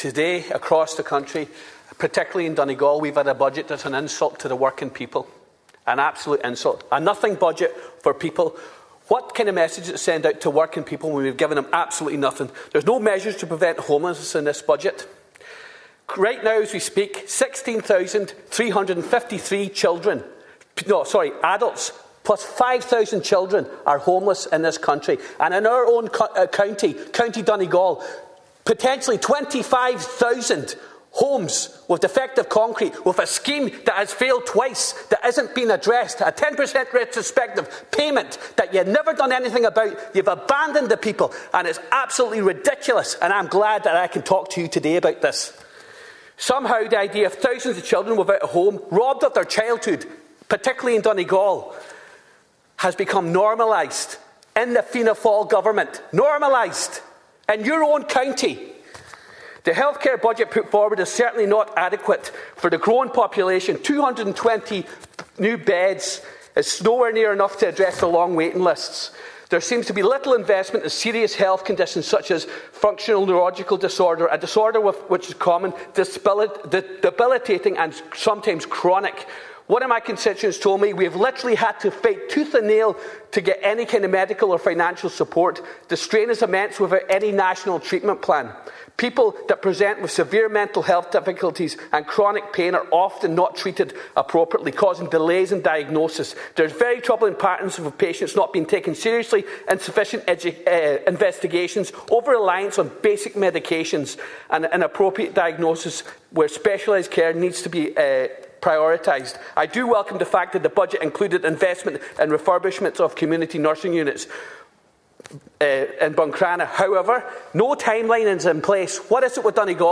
Tensions grew high in the Dáil Chamber yesterday as Deputy Charles Ward addressed Minister Charlie McConologue during his budget speech.
Deputy Ward accused the minister of abandoning Donegal: